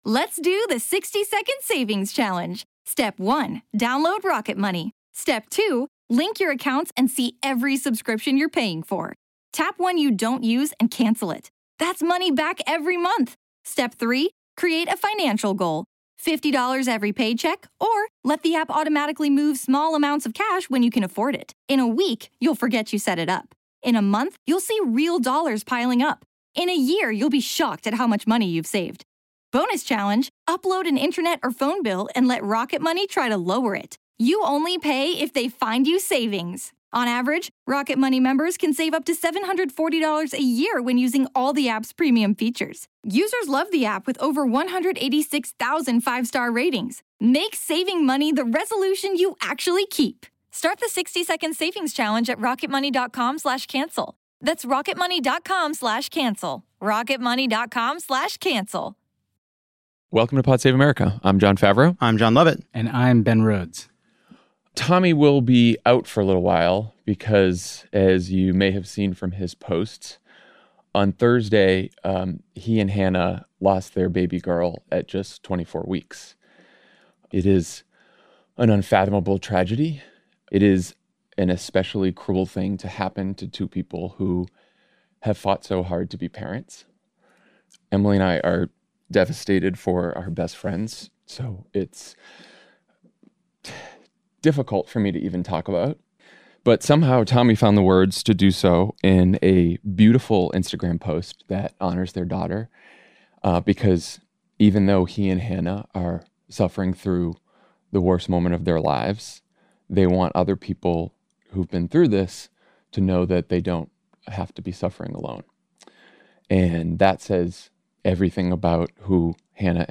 Donald Trump threatens to pardon the insurrectionists while potentially inciting new ones, guest host Ben Rhodes talks about his new Atlantic piece that focuses on how to fight Trumpism, Pennsylvania Attorney General Josh Shapiro talks about his democracy-focused campaign for Governor in what will be one of the most important races of 2022, and a wave of book bans sweeps through red states.